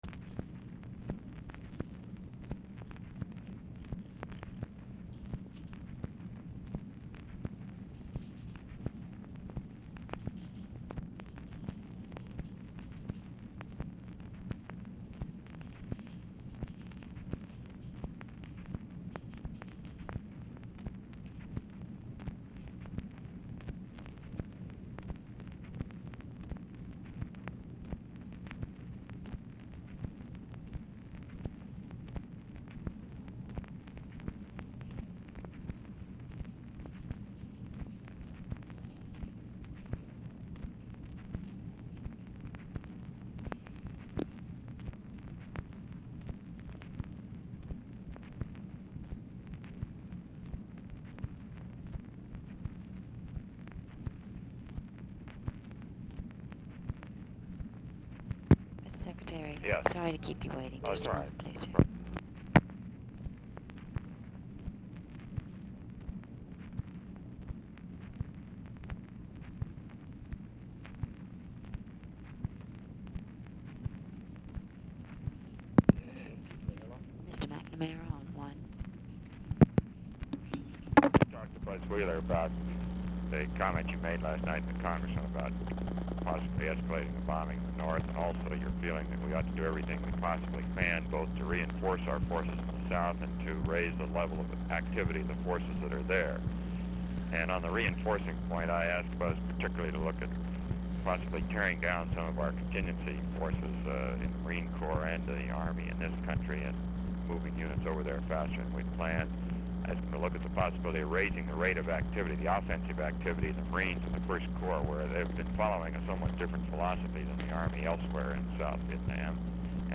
Telephone conversation
Format Dictation belt
Location Of Speaker 1 Oval Office or unknown location